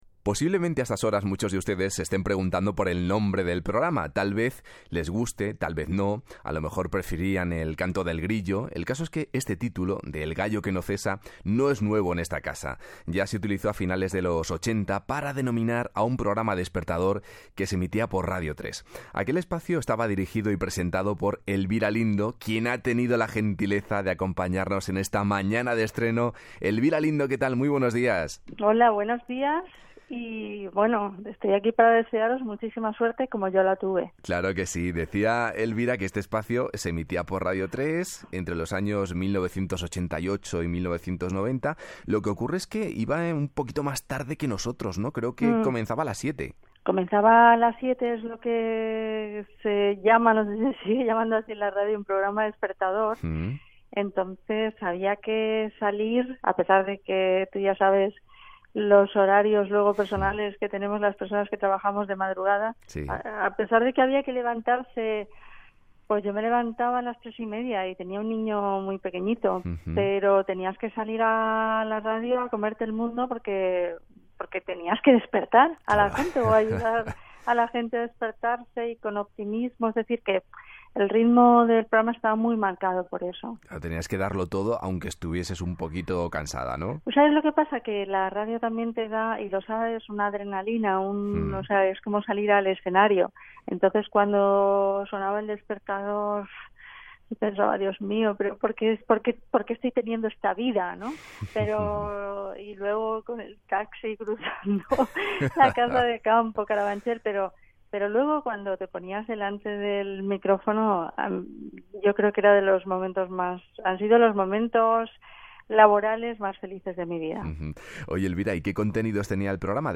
Entrevista a Elvira Lindo que recorda el programa "El gallo que no cesa" que va presentar a Radio 3 de 1988 a 1990